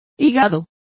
Complete with pronunciation of the translation of liver.